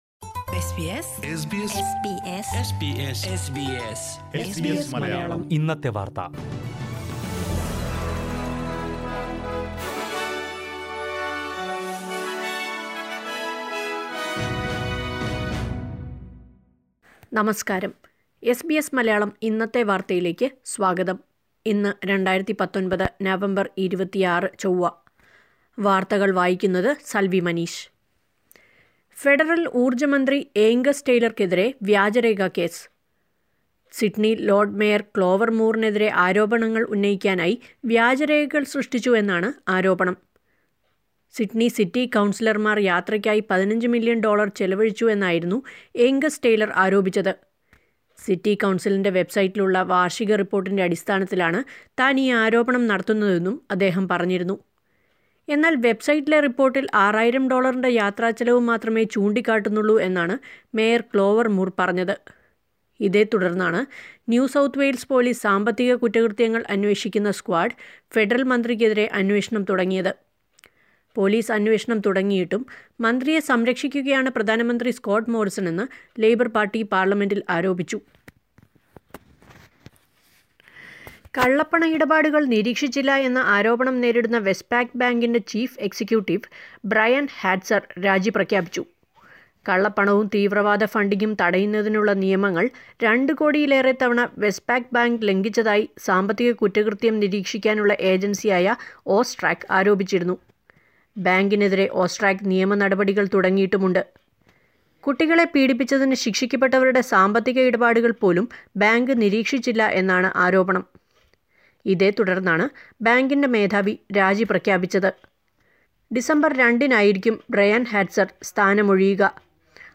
2019 നവംബർ 26ലെ ഓസ്ട്രേലിയയിലെ പ്രധാന വാർത്തകൾ കേൾക്കാം...